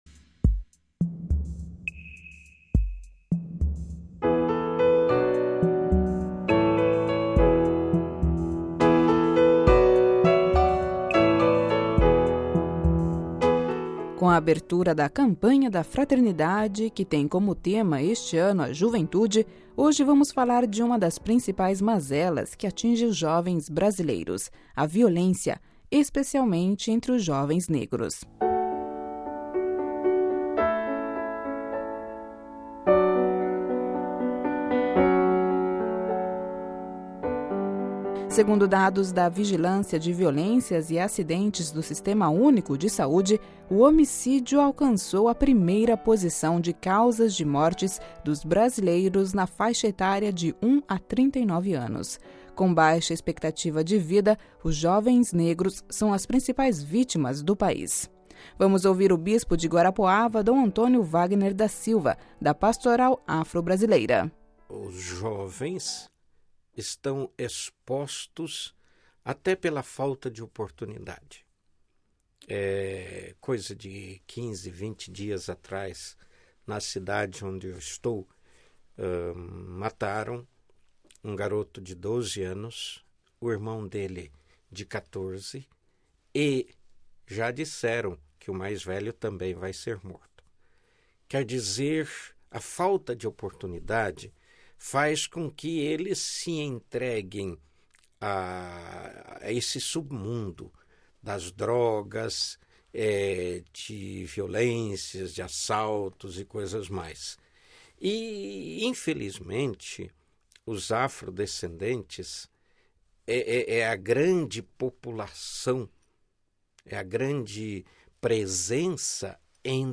Sobre esta situação, a Rádio Vaticano contatou o Bispo de Guarapuava (PR), Dom Antônio Wagner da Silva, da Pastoral Afro-brasileira.